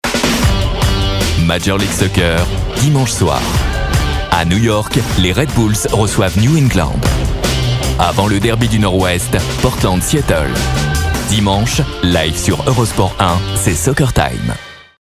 EUROSPORT sportif - Comédien voix off
Genre : voix off.